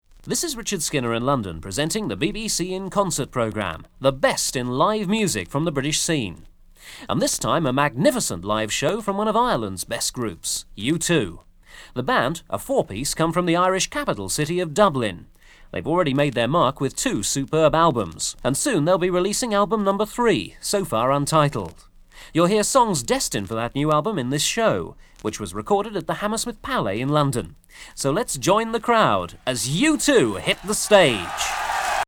I cleaned up a few crackles and pops during the ripping process, but there are still a few noticeable ones on side 2.
Recorded live at the Hammersmith Palais on 12/6/1982